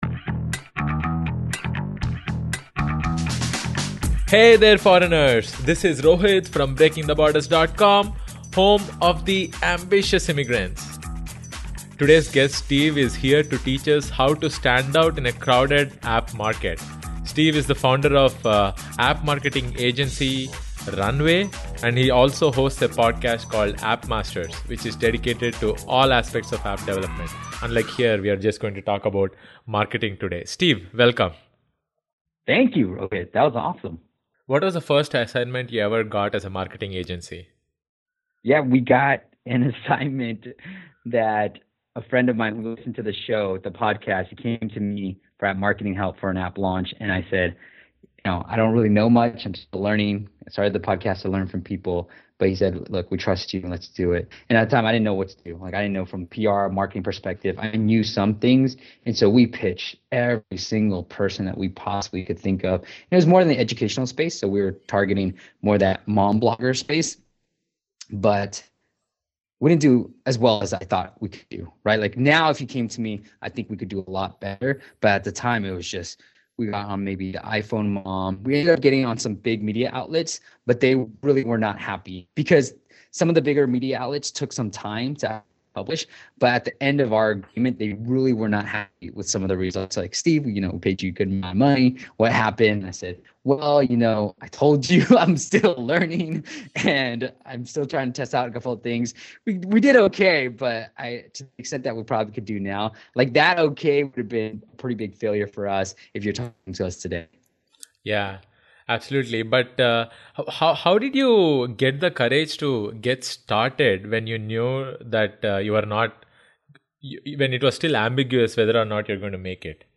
You will learn the following in this interview: